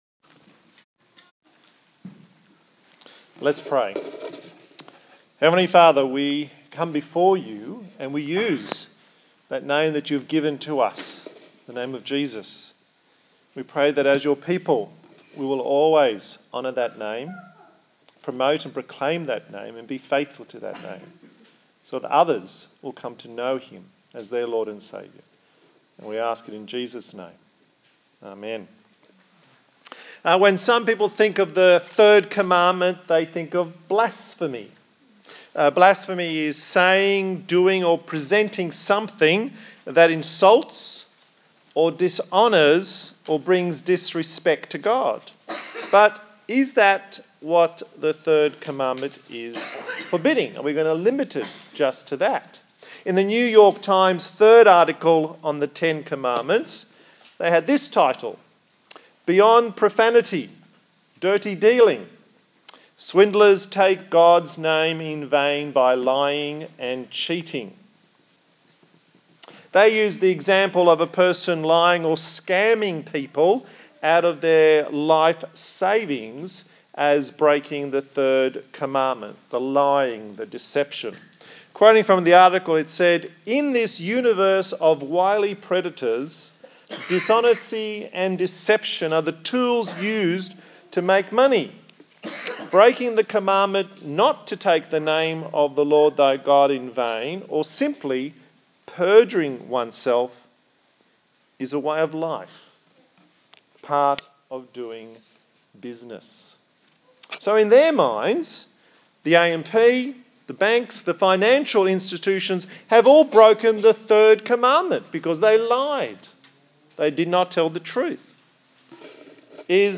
A sermon in the series on the Ten Commandments